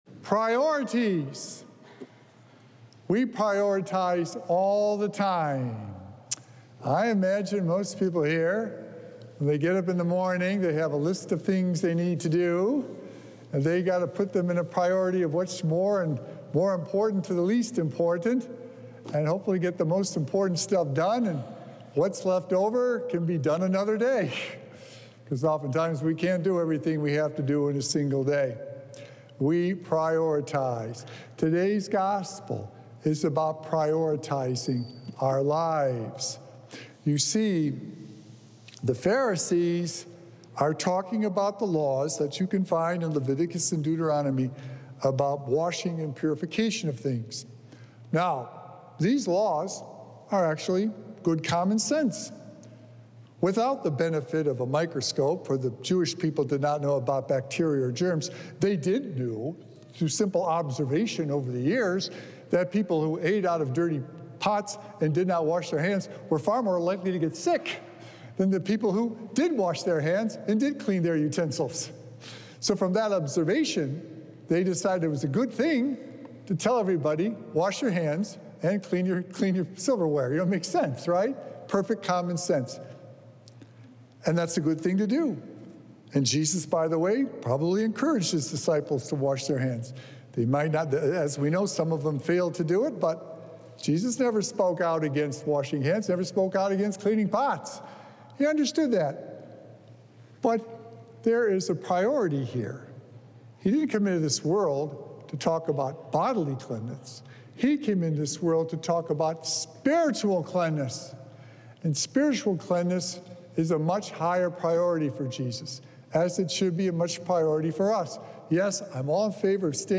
Sacred Echoes - Weekly Homilies Revealed
Recorded Live on Sunday, September 1st, 2024 at St. Malachy Catholic Church.